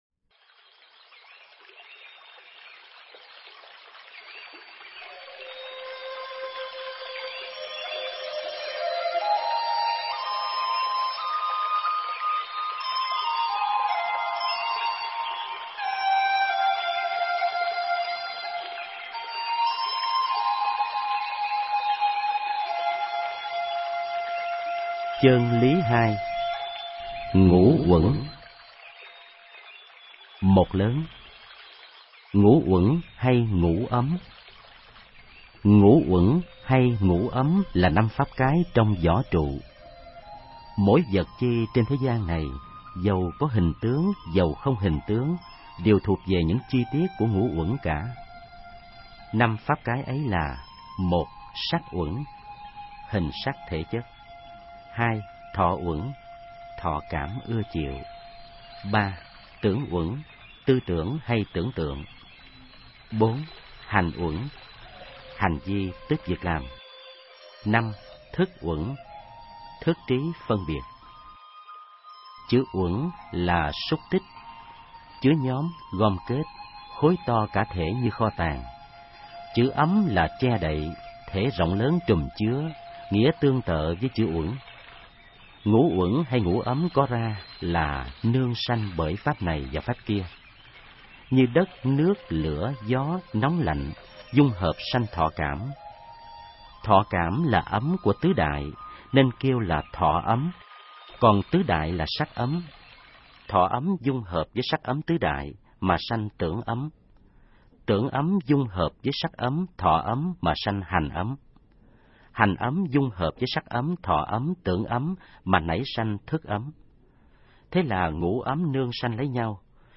Nghe sách nói chương 02. Ngũ Uẩn